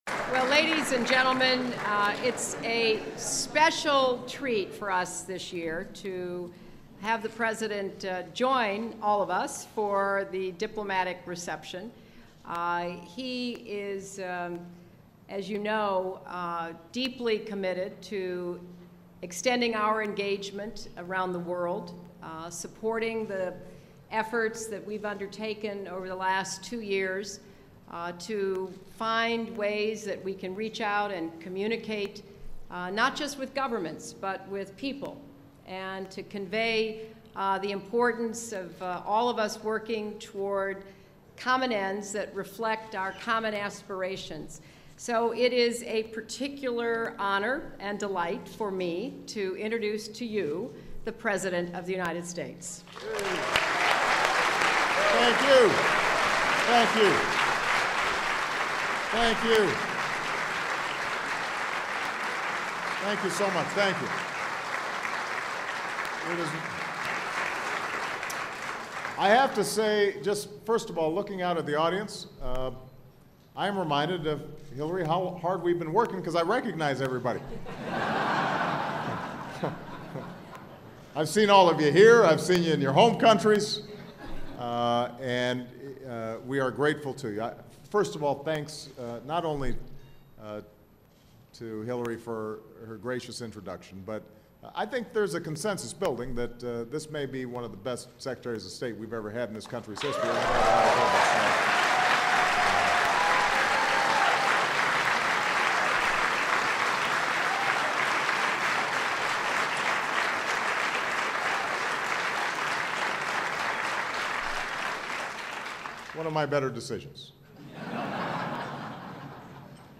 U.S. President Barack Obama speaks at the foreign diplomatic corps holiday reception